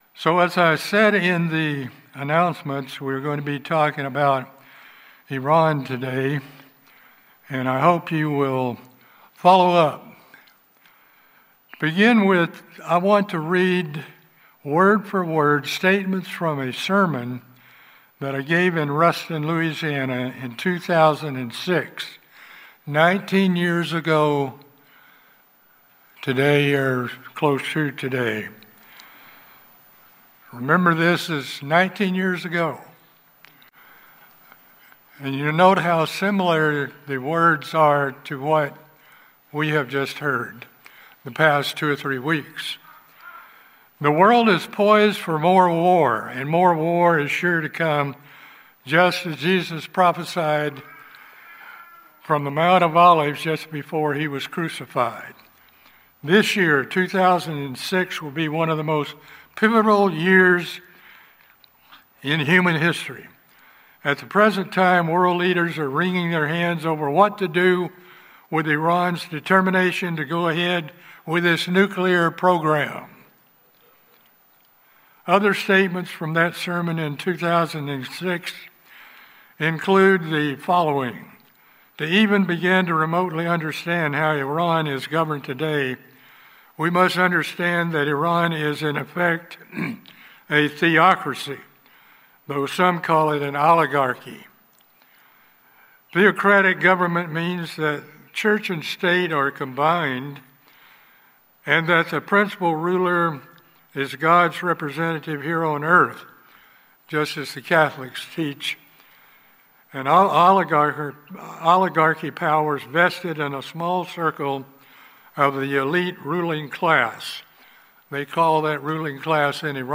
This sermon provides an overview of Persia/Iran in prophecy.